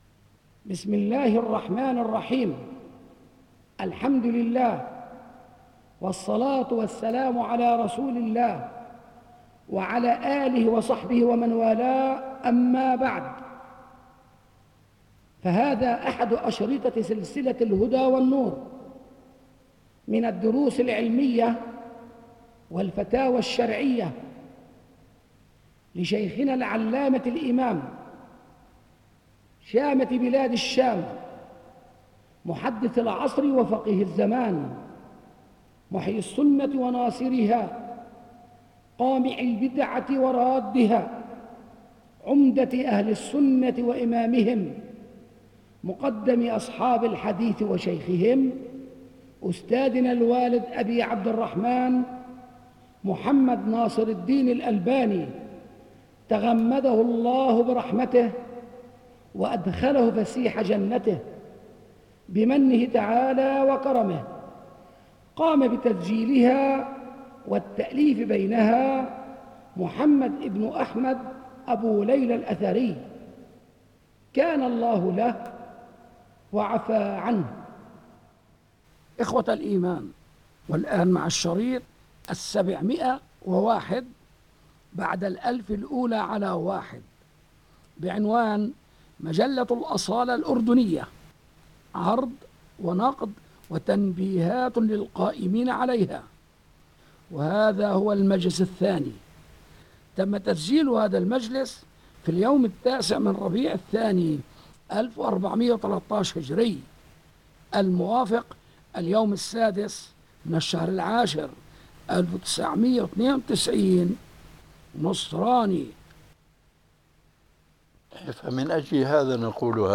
بِصوتِ الإِمامِ الألبَانِي